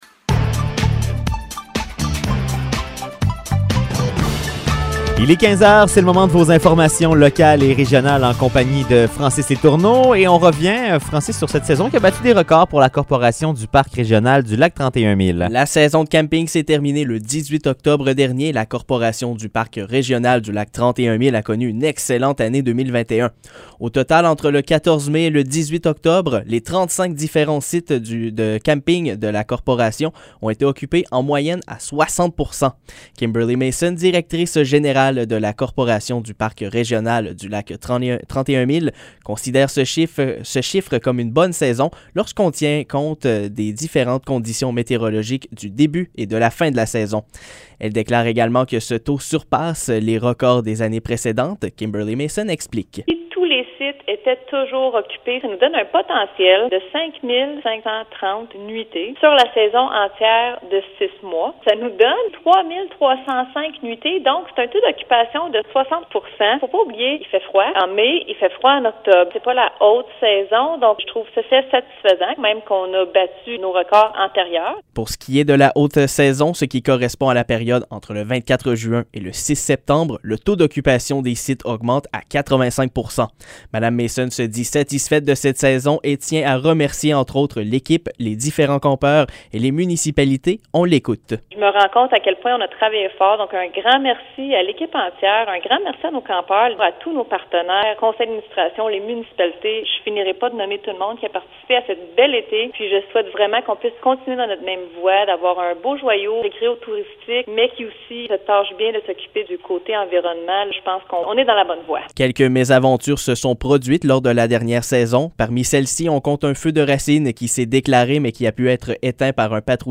Nouvelles locales - 22 octobre 2021 - 15 h